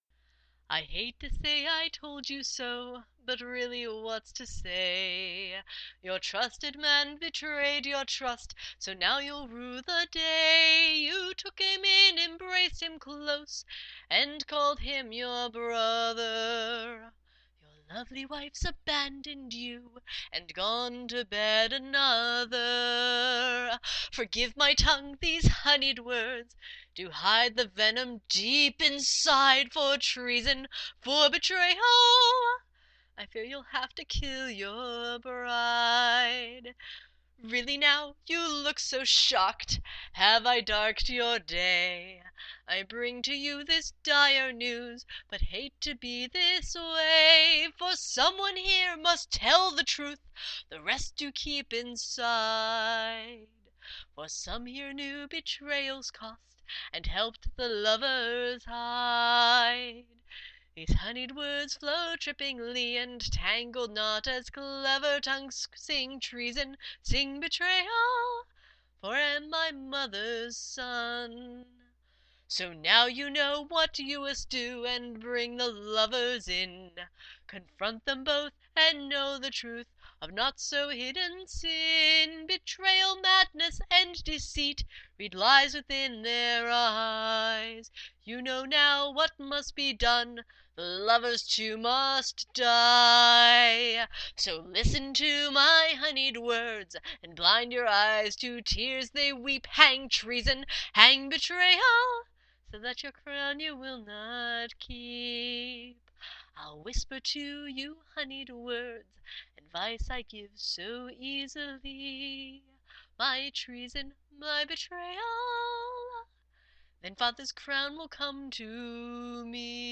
Honeyed-Words-Final-Tune-rough-cut.mp3